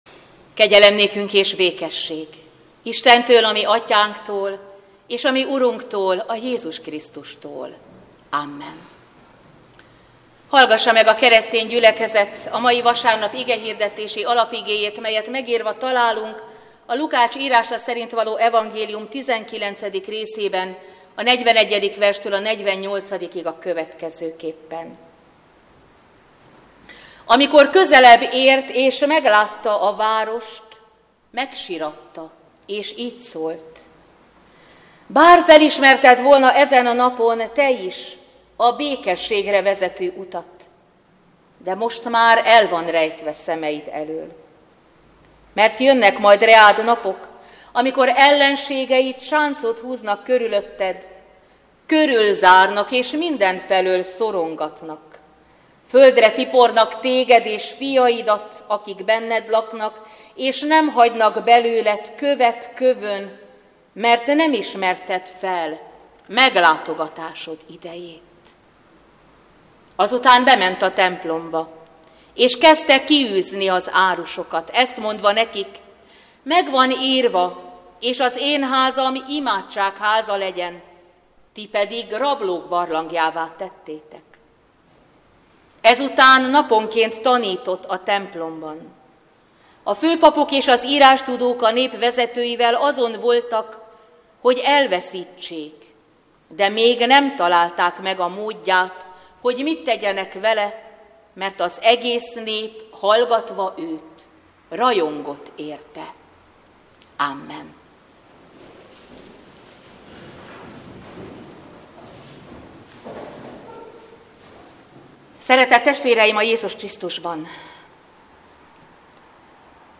Szentháromság ünnepe után 10. vasárnap - Boldog az a nemzet, amelynek Istene az Úr, az a nép, amelyet örökségül választott.
Igehirdetések